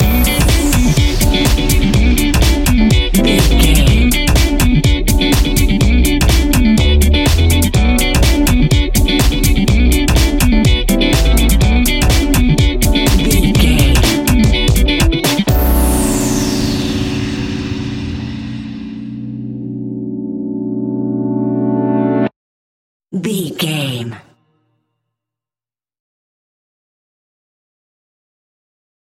Aeolian/Minor
groovy
uplifting
energetic
bass guitar
synthesiser
electric guitar
drums
piano
nu disco
upbeat
instrumentals